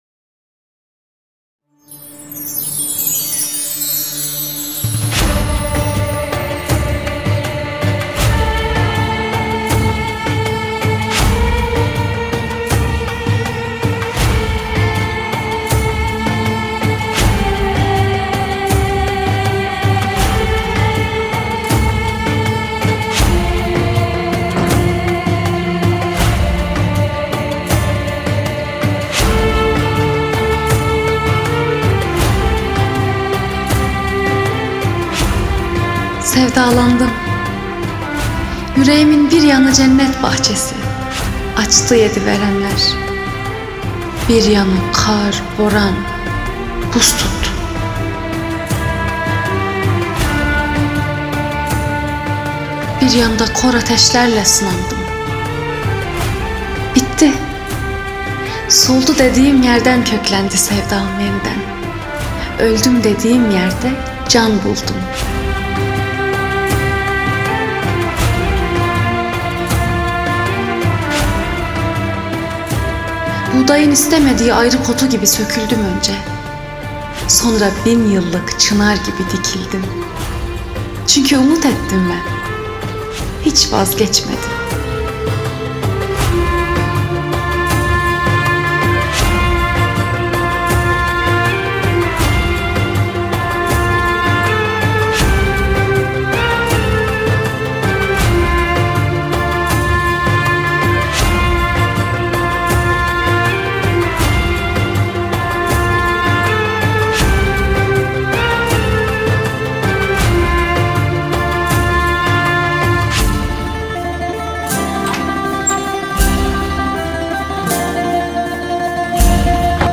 dizi müziği, duygusal huzurlu rahatlatıcı şarkı.